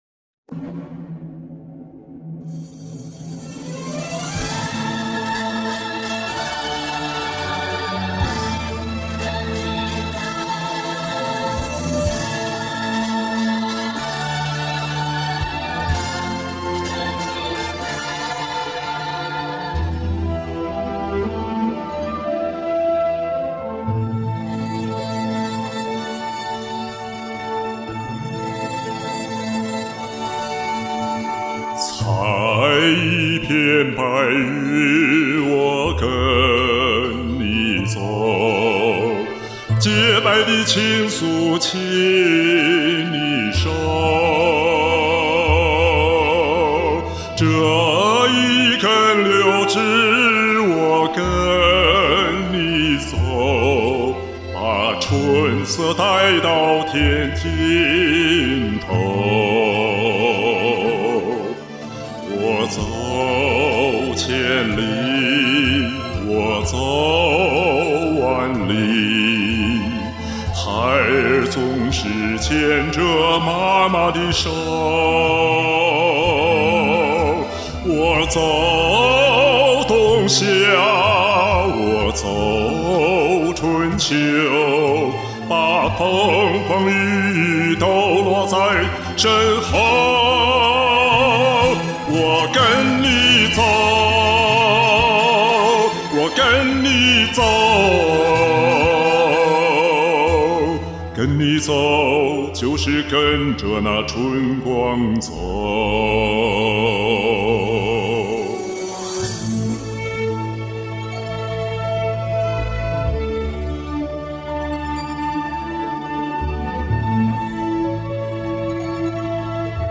又听到浑厚饱满大气的声音！
久违了这醇厚又有磁性的嗓音，沉醉于你的歌声中。
歌声透着豪迈